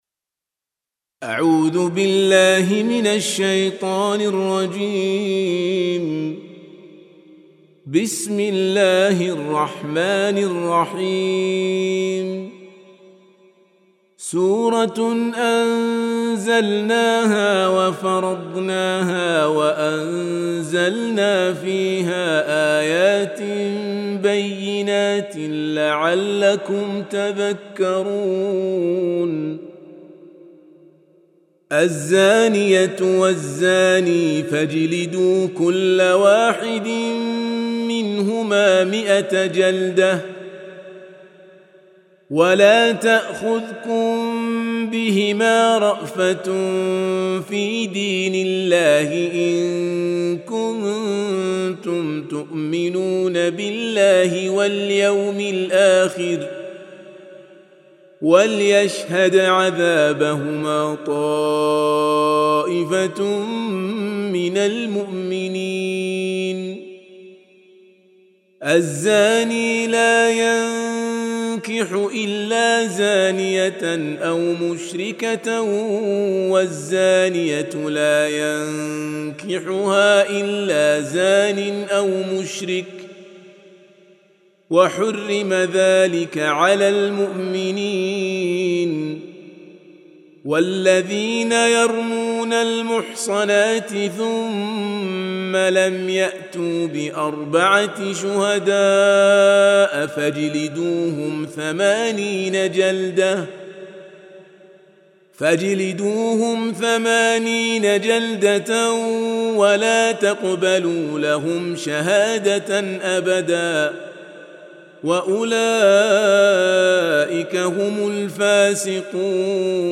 Surah Repeating تكرار السورة Download Surah حمّل السورة Reciting Murattalah Audio for 24. Surah An-N�r سورة النّور N.B *Surah Includes Al-Basmalah Reciters Sequents تتابع التلاوات Reciters Repeats تكرار التلاوات